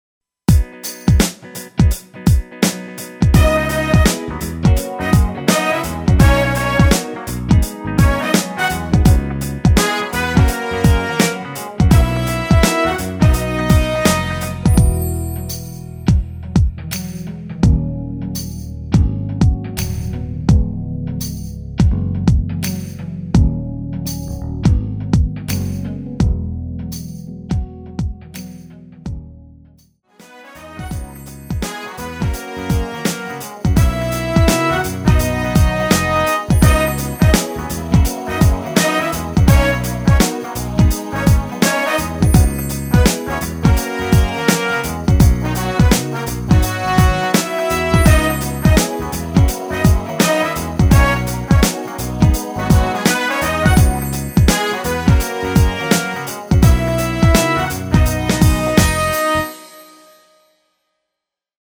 전주가 길어서 짧게 편곡 하였으며 브라스 악기 끝나고 15초쯤 노래 들어 가시면 됩니다.
◈ 곡명 옆 (-1)은 반음 내림, (+1)은 반음 올림 입니다.
앞부분30초, 뒷부분30초씩 편집해서 올려 드리고 있습니다.
중간에 음이 끈어지고 다시 나오는 이유는